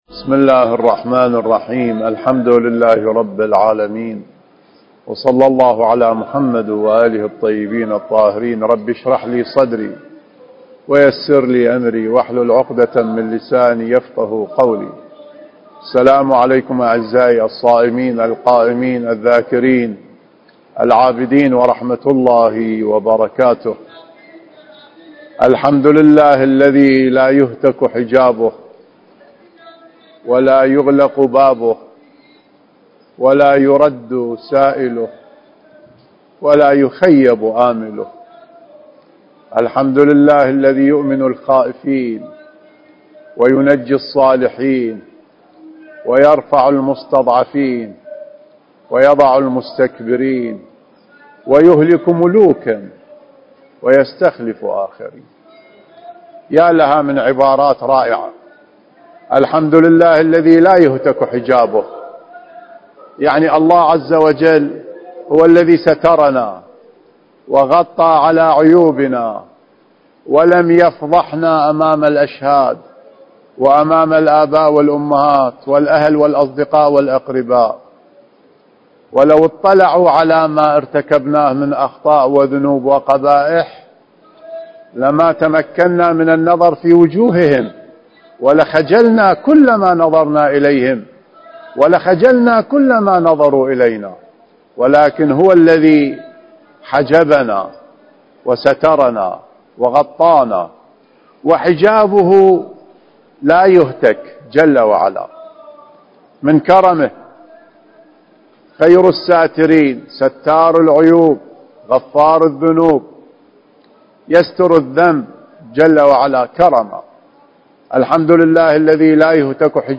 المكان: مسجد الكوفة